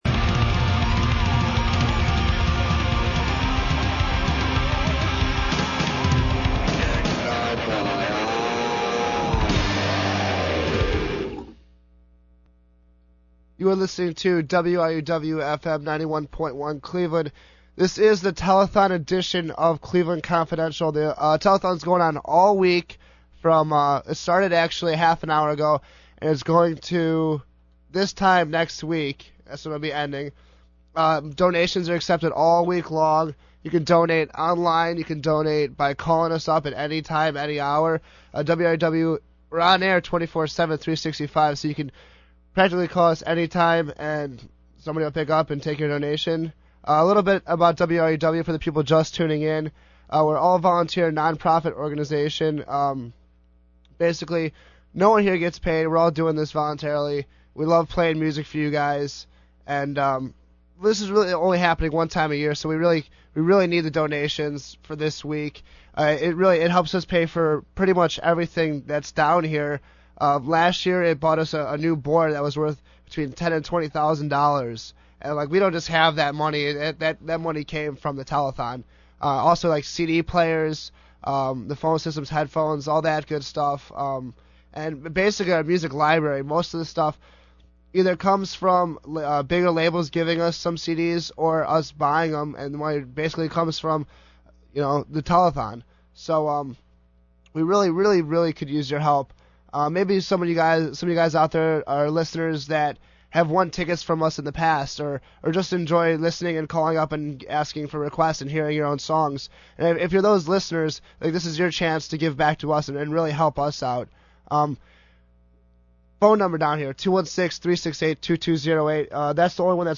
• Listen to how positive and upbeat each programmer is when talking about the fundraiser.